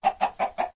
minecraft / sounds / mob / chicken / say2.ogg